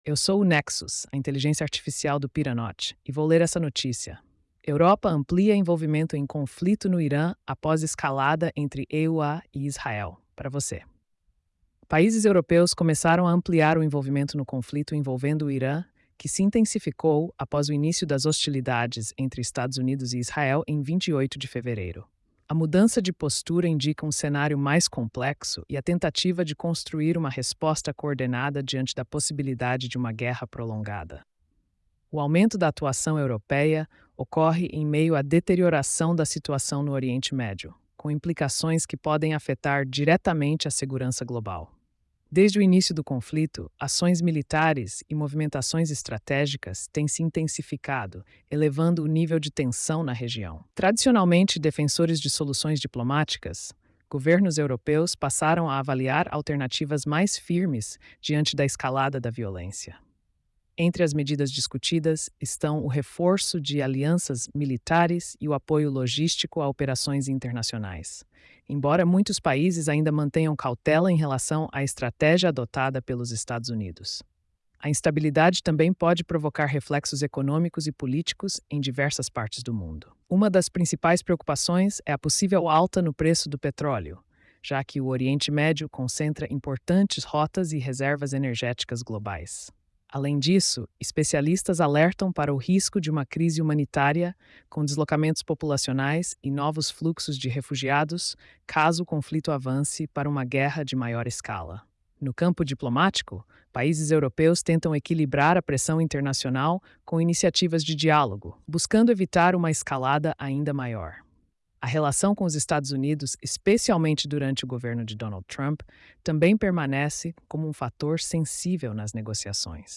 Nexus AI · Ouca esta noticia · 2:51